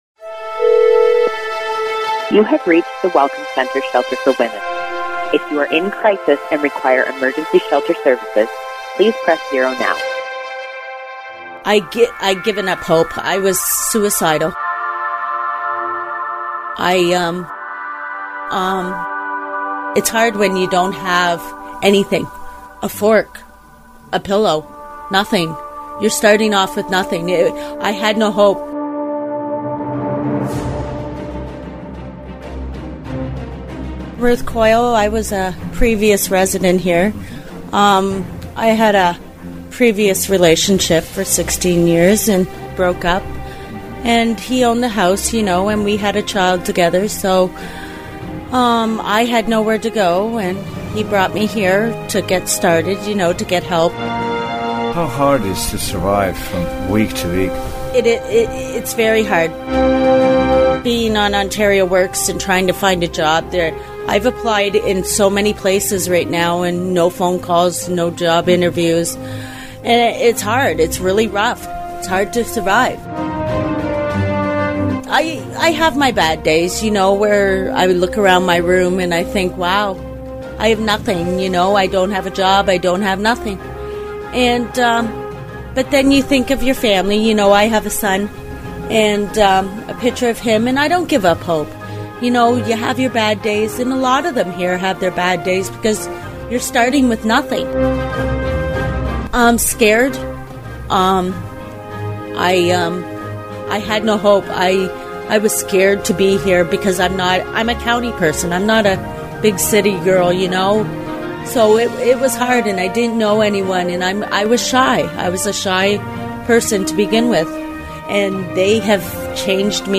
Recording Location: Windsor, Ontario
Type: News Reports
0kbps Stereo